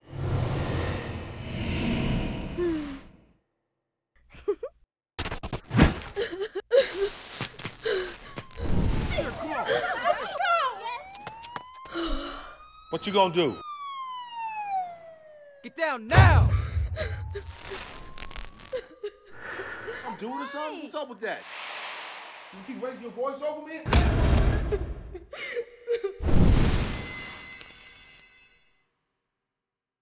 FA103_AllNew_NextMonday30_sfx.wav